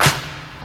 • 80s Reverb Pop Clap Sound Clip D Key 08.wav
Royality free clap sound clip - kick tuned to the D note. Loudest frequency: 2941Hz
80s-reverb-pop-clap-sound-clip-d-key-08-fL6.wav